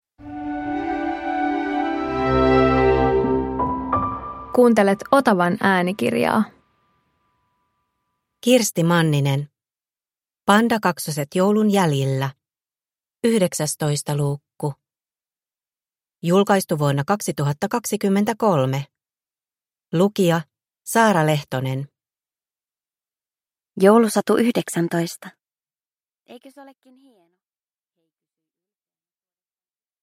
Pandakaksoset joulun jäljillä 19 – Ljudbok